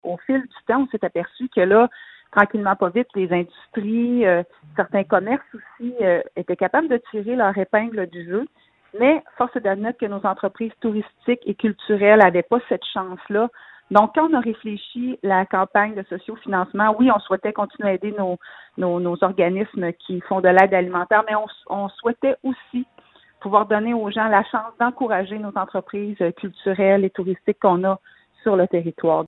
La préfète de la MRC de Nicolet-Yamaska, Geneviève Dubois, explique, en audio ci-joint, ce qui a motivé cette campagne en deux volets :